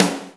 SNARE 003.wav